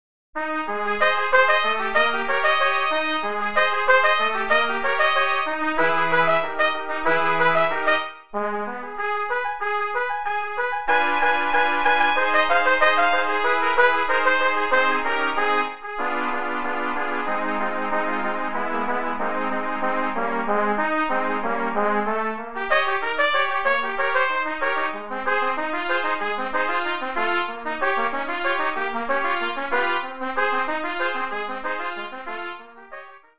5 Trumpets
happy, angular melody